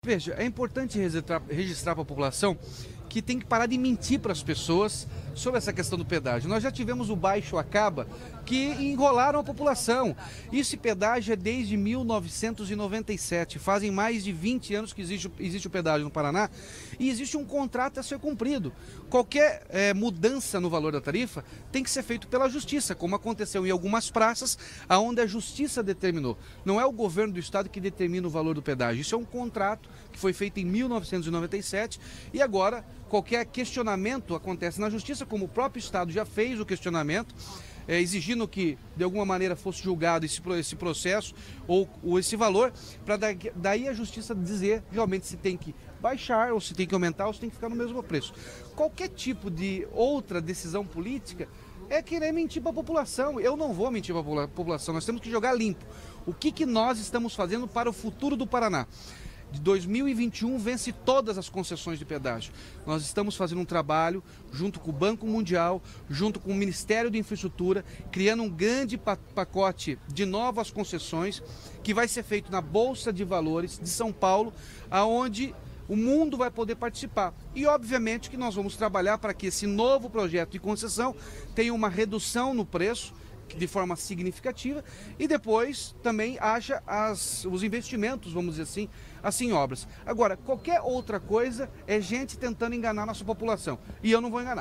Durante entrevista coletiva ontem (16), o governador Ratinho Junior falou sobre os pedágios no Paraná.